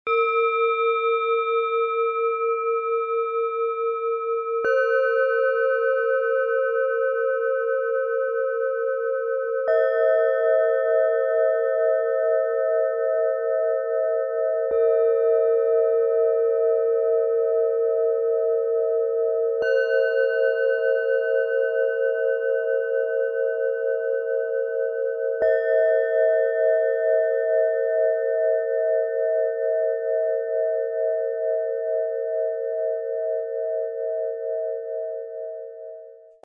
Set aus 3 Planetenschalen, Ø 10,7 - 12,2 cm, 1,01 kg
Jede Schale ist ein Unikat mit einzigartiger Schwingung - fein abgestimmt und mit stimmiger Resonanz.
Erlebe die Kraft von Ruhe, Ursprung und mentaler Klarheit - mit diesem feinen Dreiklang aus Mond, DNA und Alphawellen.
Dank unseres Sound-Player - Jetzt reinhörens können Sie den echten Klang dieser speziellen Schalen des Sets selbst anhören.
Tiefster Ton: Mond
Mittlerer Ton: DNA
Höchster Ton: Alphawellen
MaterialBronze